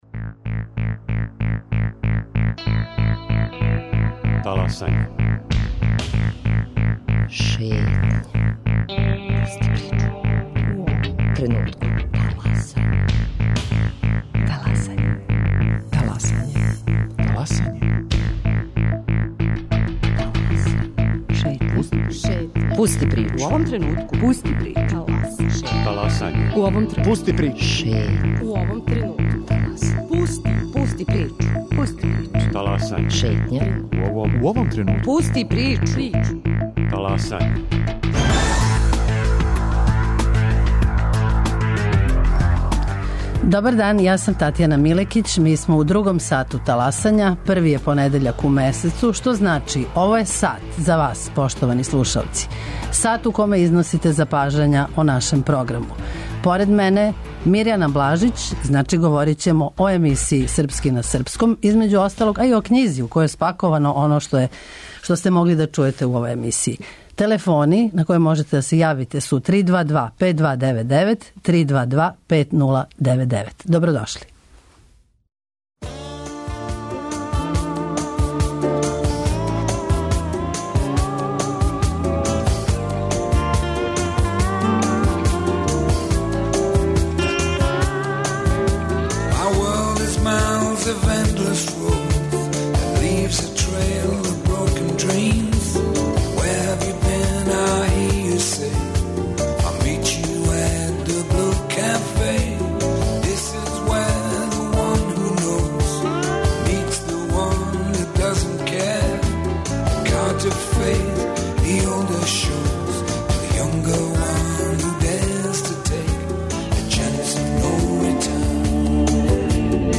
Као и сваког првог понедељка у месецу, и данас вам пружамо прилику да 'таласате' заједно са нама. Ви причате, сугеришете, коментаришете - ми слушамо!